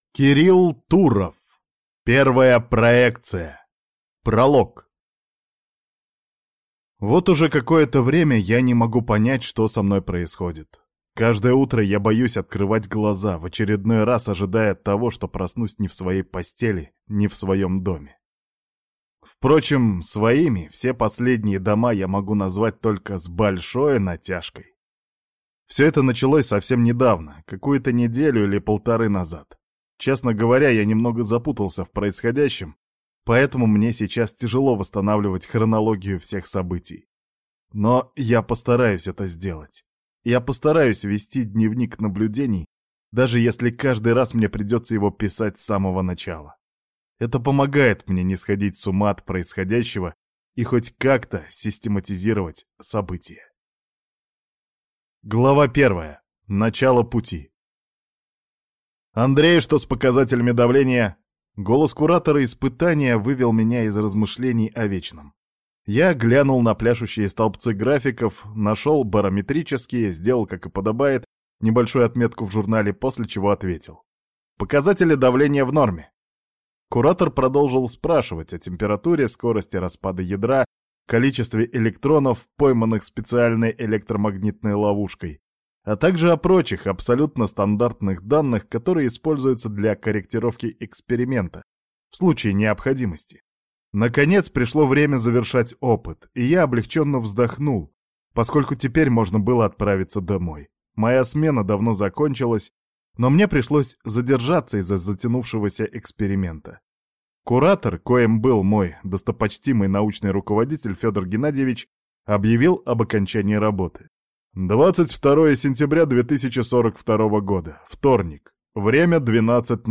Аудиокнига Первая проекция | Библиотека аудиокниг